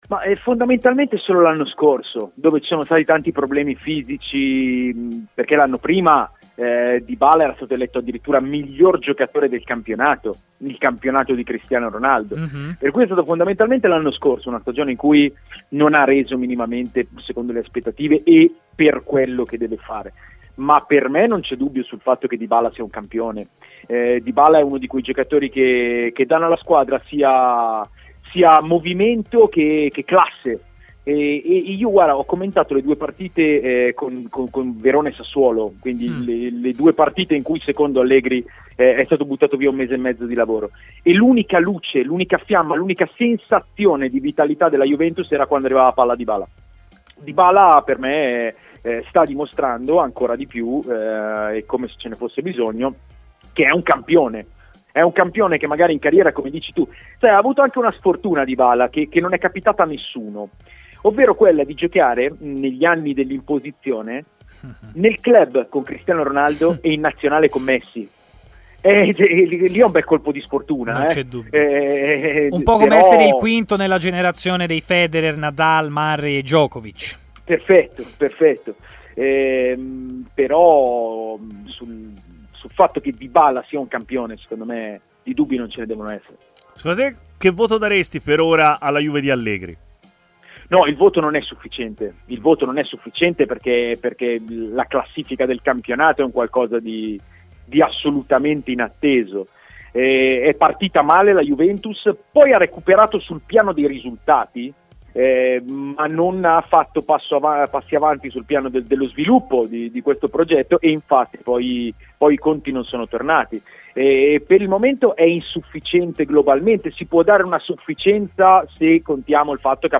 è intervenuto in diretta durante Stadio Aperto, trasmissione di TMW Radio